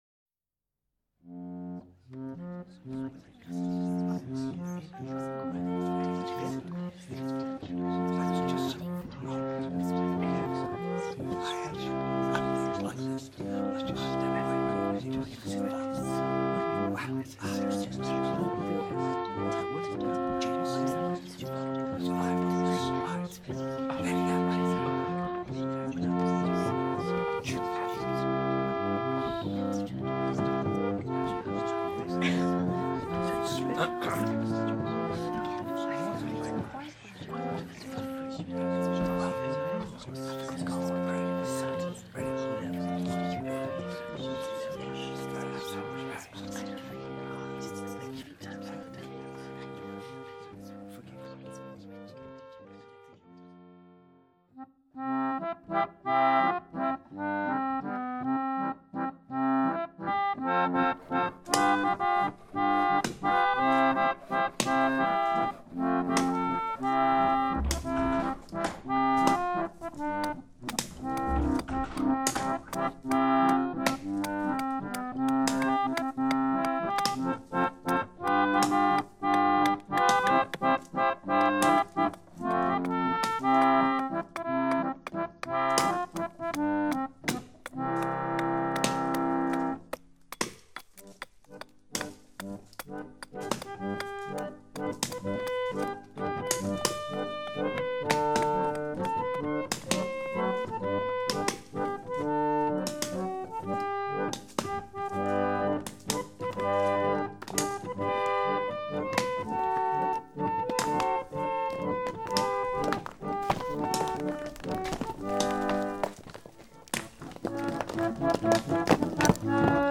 Melodeon pieces
melodeon.wma